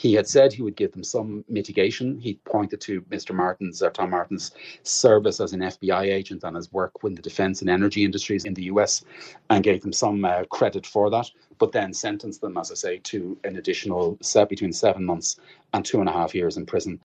journalist